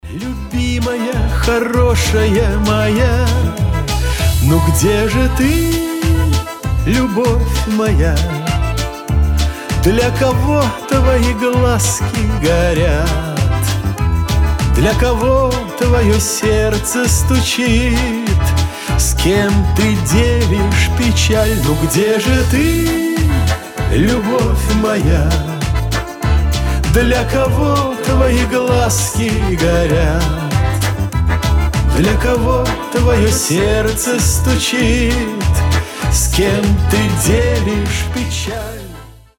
эстрадные , аккордеон